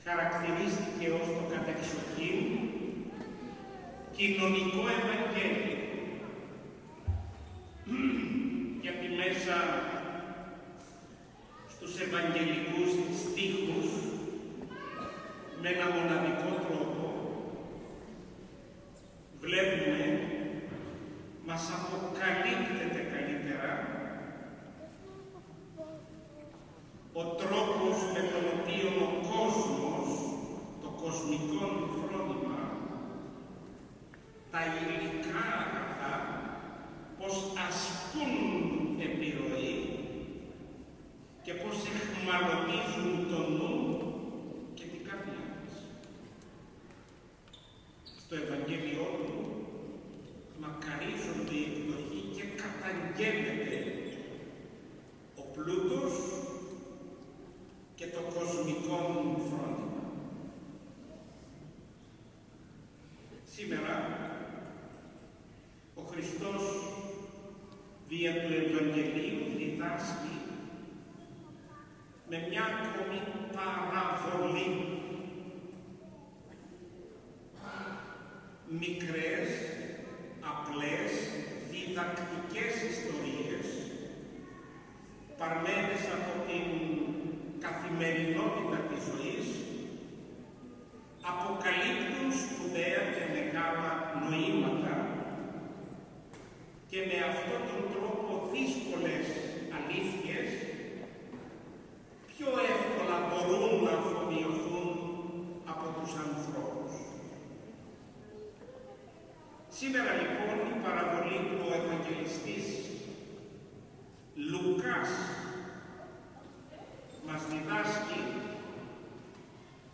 Στον Ιερό Καθεδρικό Ναό του Τιμίου Προδρόμου στη Νεάπολη ιερούργησε και τέλεσε το τεσσαρακονθήμερο μνημόσυνο του Ολυμπιονίκη Αλέξανδρου Νικολαΐδη την Κυριακή 20 Νοεμβρίου 2022 ο Σεβασμιώτατος Μητροπολίτης Νεαπόλεως και Σταυρουπόλεως κ. Βαρνάβας.
ΚΗΡΥΓΜΑ.mp3